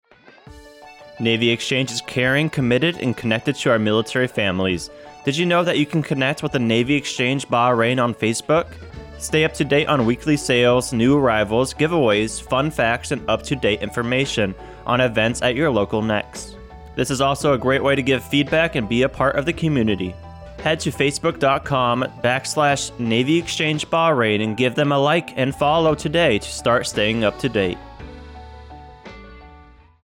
Radio SpotMWR Bahrain